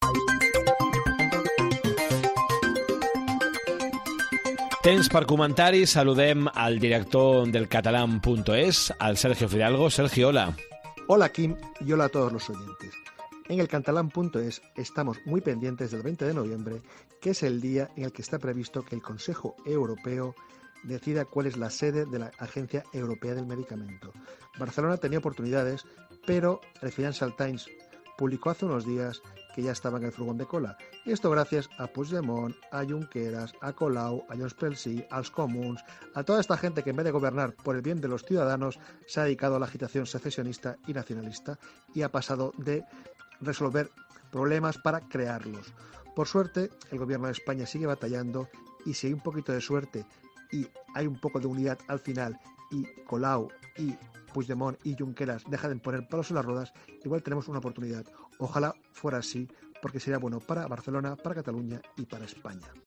Comentari